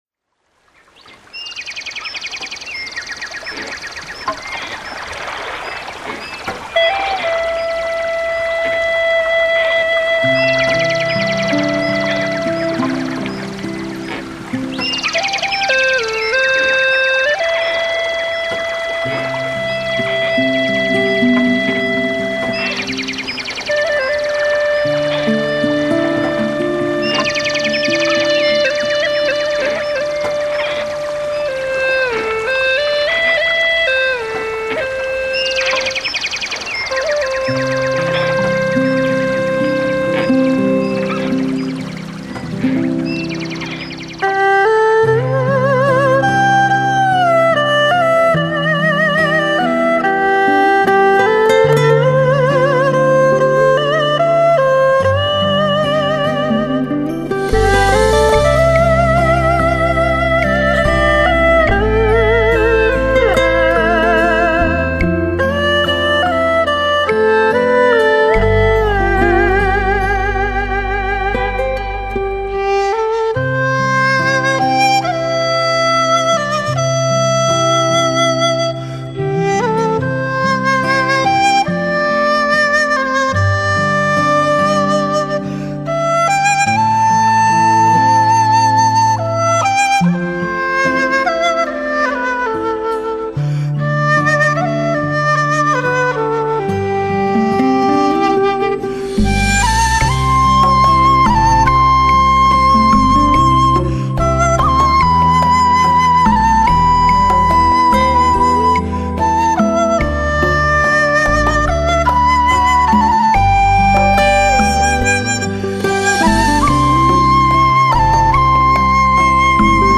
创世纪音乐盛宴，空灵与辽阔的余韵，
顶级经典冲击你的心灵 极美清澈的天籁之音 仿佛来自天际
将古筝、葫芦丝、大小提琴、笛子、琵琶等乐器融合
多种乐器演奏名家演奏收录，演录俱佳极品典范
小提琴
大提琴
二胡
古筝
琵琶
葫芦丝
笛子
吉他
长笛
笙